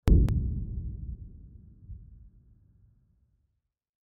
دانلود آهنگ تصادف 4 از افکت صوتی حمل و نقل
دانلود صدای تصادف 4 از ساعد نیوز با لینک مستقیم و کیفیت بالا
جلوه های صوتی